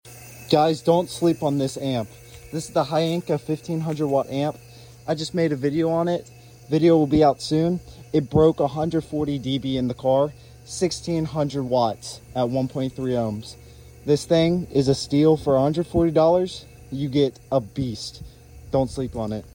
This Amp Broke 140 Db Sound Effects Free Download